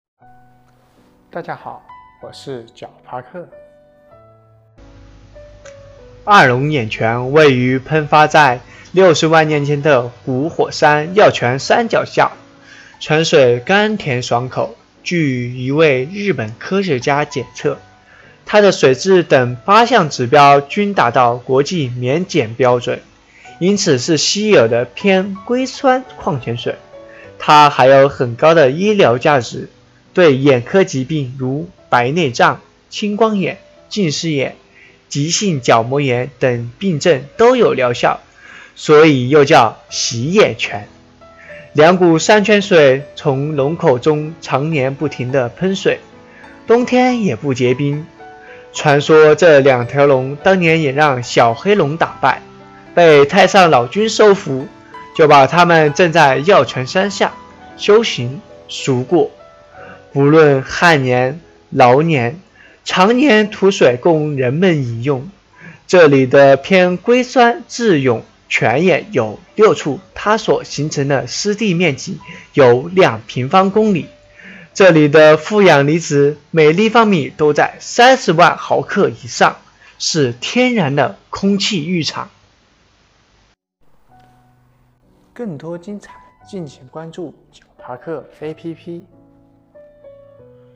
解说词